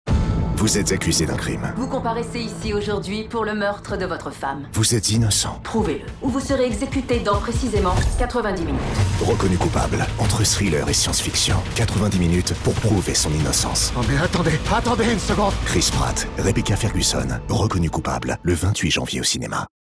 Thriller tendu et dynamique.
Spot radio du film « Reconnu coupable ».
Enregistré et mixé chez Badje.
Reconnu-coupable-spot-radio.mp3